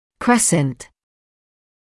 [‘kresnt][‘крэснт]полумесяц; серп; серповидный